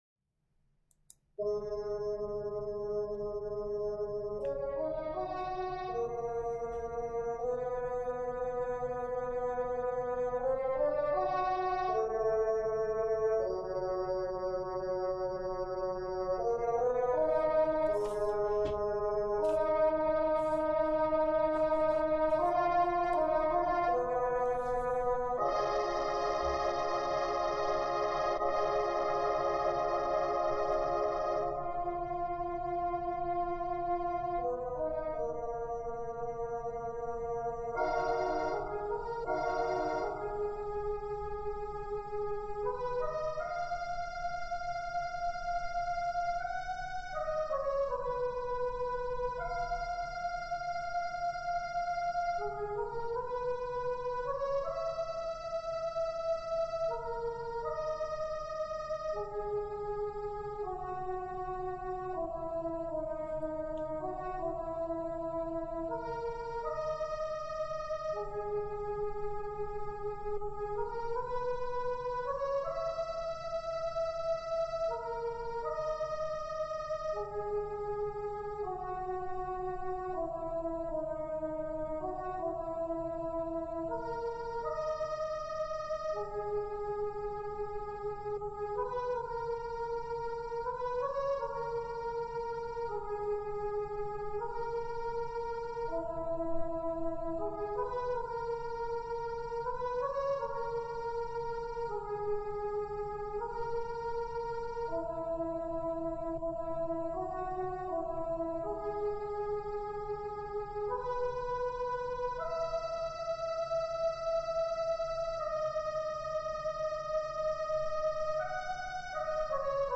\tempo 4 = 80
instrument = "synthstrings 1, pad 4 (choir)"
meter = "Andantino"